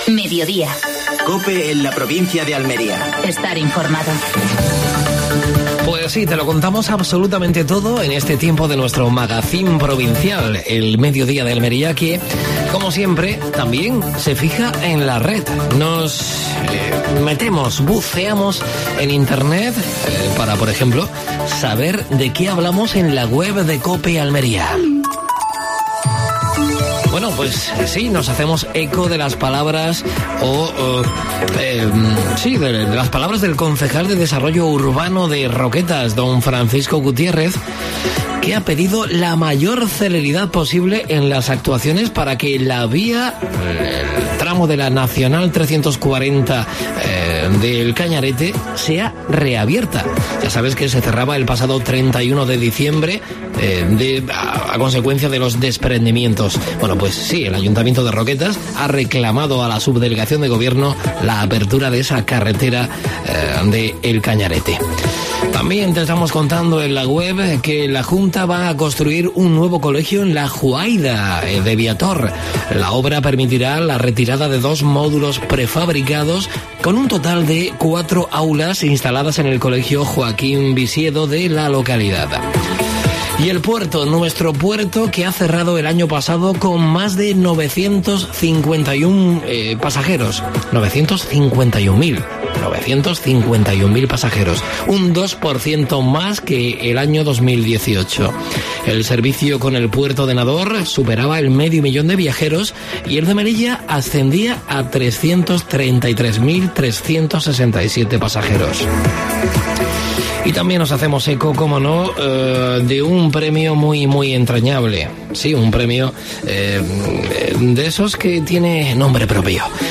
AUDIO: Actualidad en Almería. Entrevista al alcalde de Almería, Ramón Fernández-Pacheco.